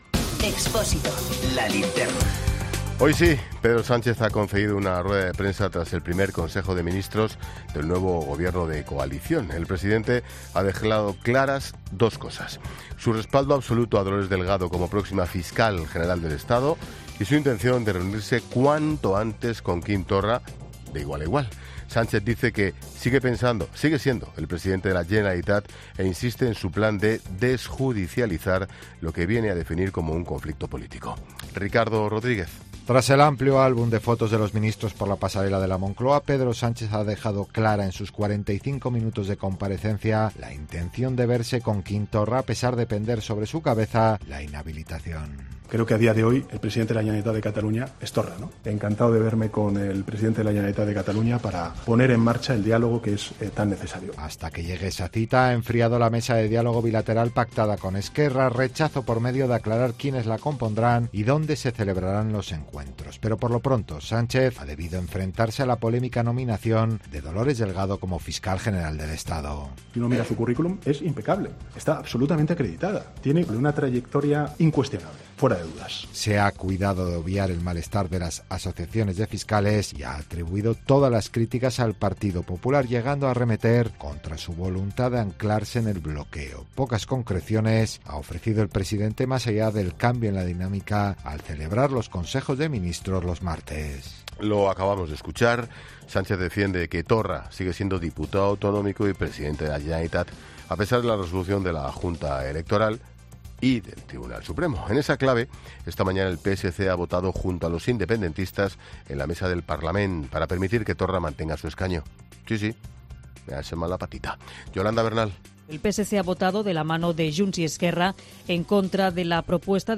Boletín informativo de COPE del 14 de enero de 2020 a las 19 horas